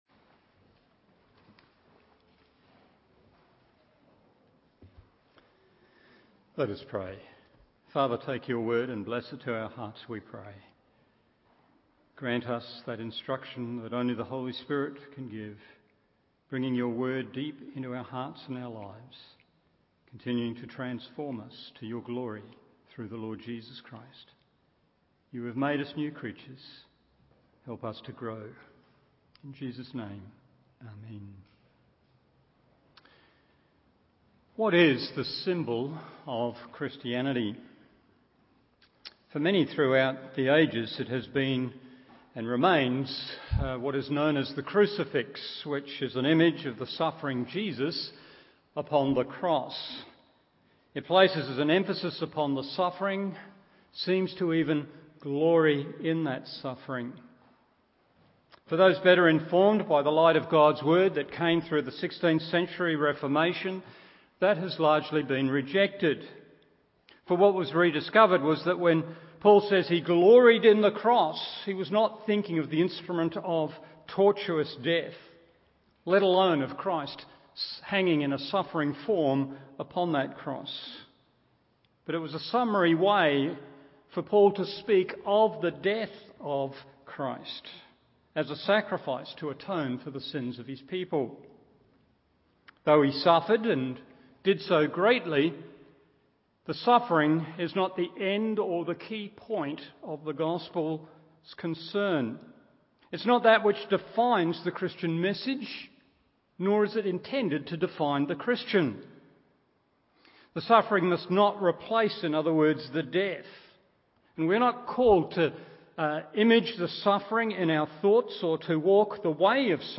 Evening Service Hebrews 10:14 1.